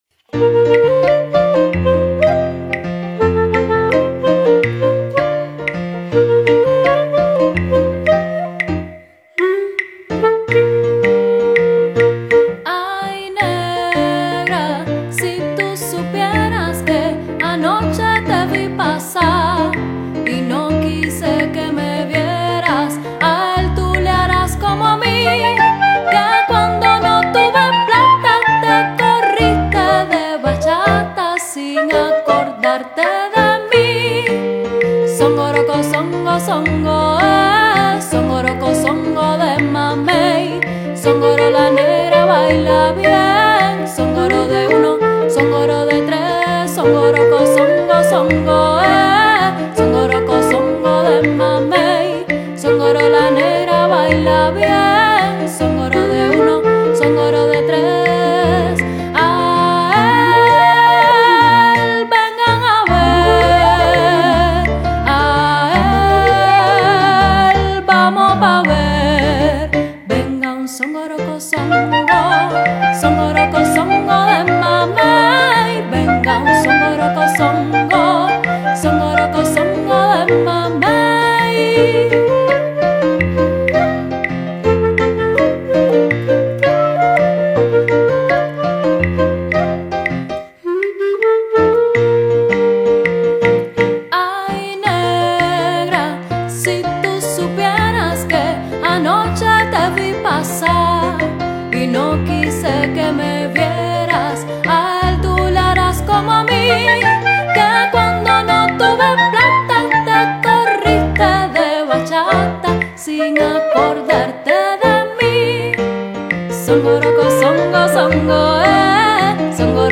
popular latin-american song
clarinet
piano